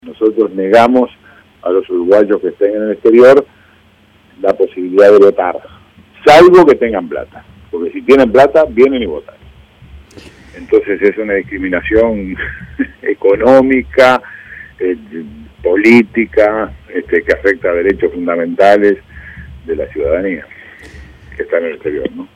En diálogo con El Espectador, el canciller sostuvo que "hoy Uruguay tiene el triste papel de ser el único país de América del Sur que no tiene el voto para sus compatriotas que están en el exterior".